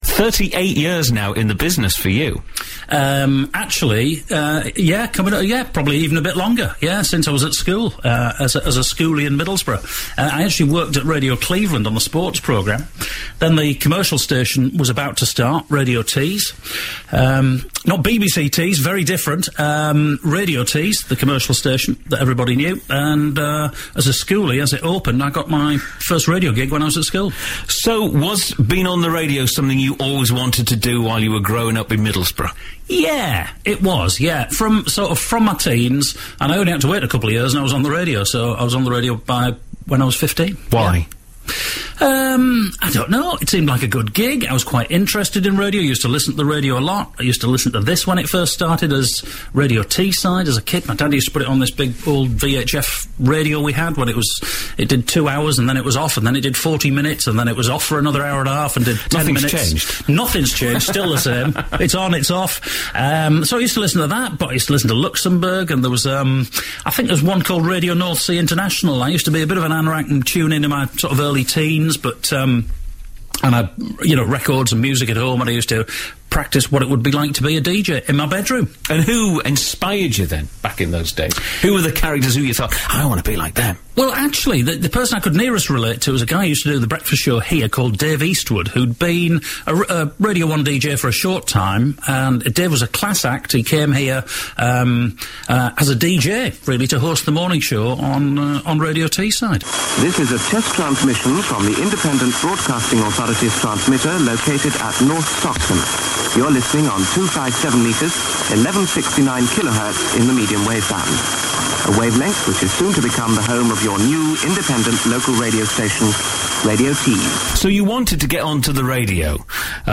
Recalling those days here’s Mark in conversation